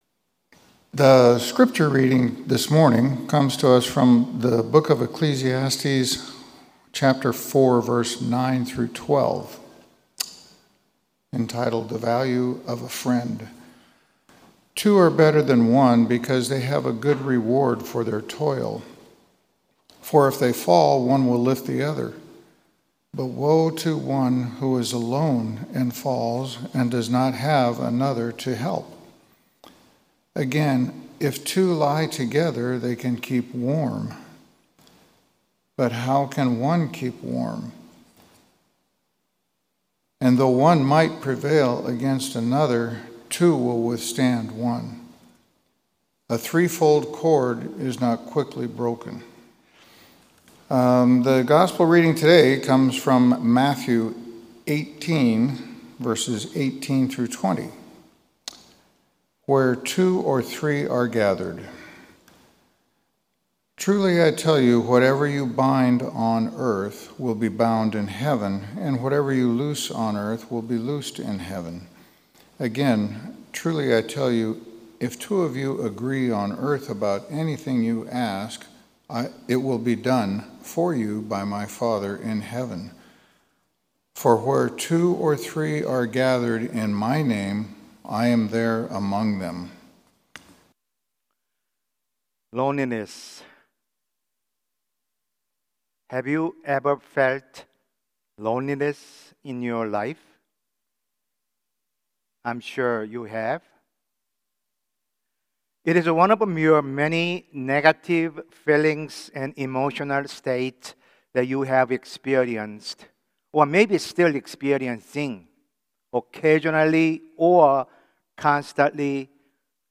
Sermon – Methodist Church Riverside
Thirteenth Sunday after Pentecost sermon